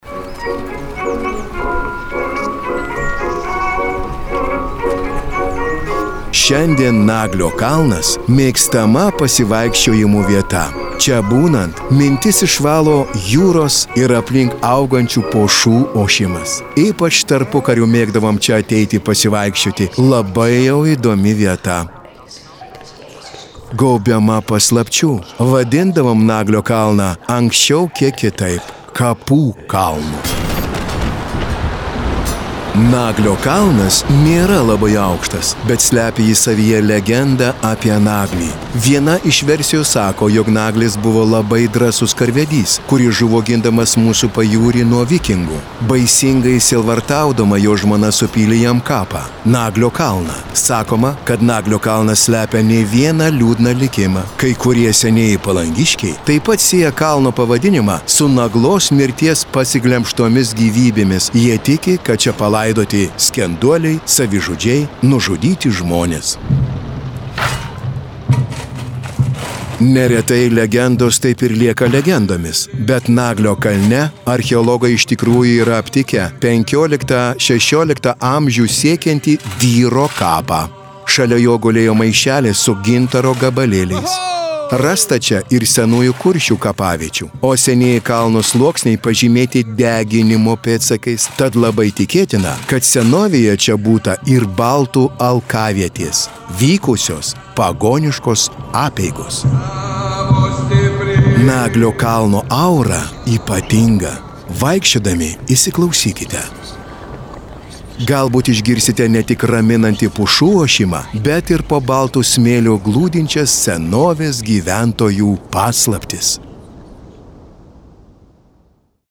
Projekto „Kurortų paveldas išmaniai“ Palangos objektų audiogidai: